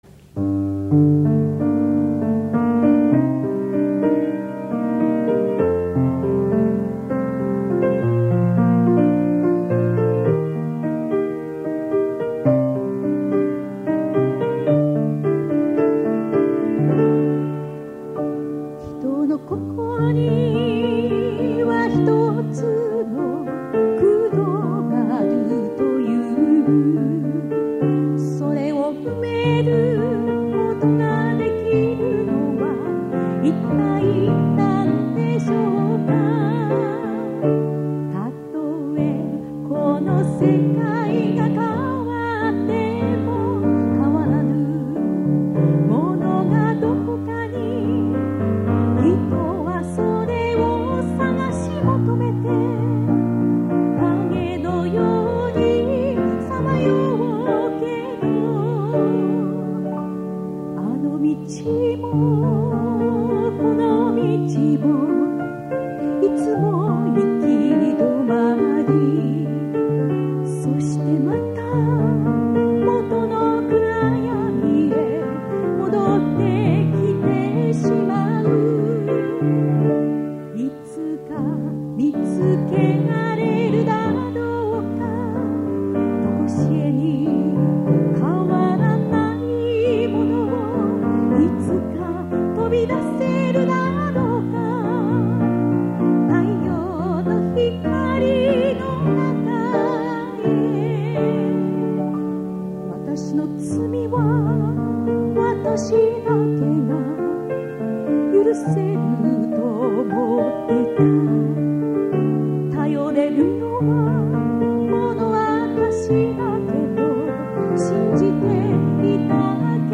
フライデーナイトミニコンサート、お茶の水クリスチャンセンター、1980年代後半
gospel singer